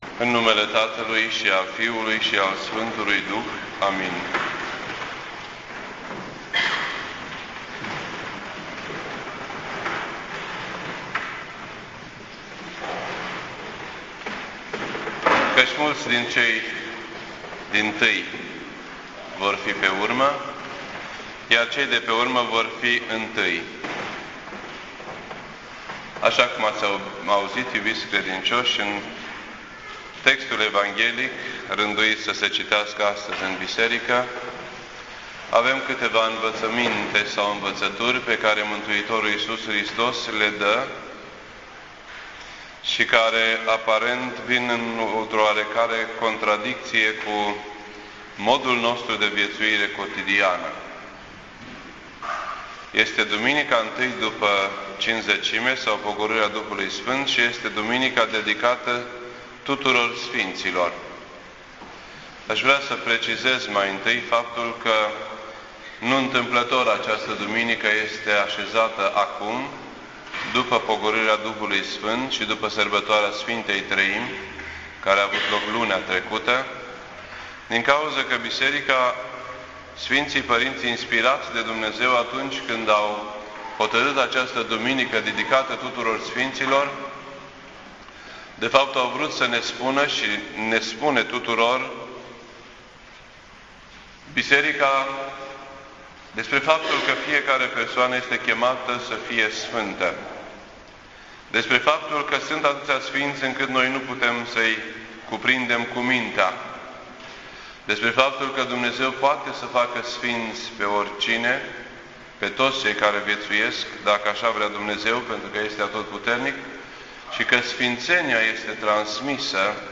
This entry was posted on Sunday, June 19th, 2011 at 7:13 PM and is filed under Predici ortodoxe in format audio.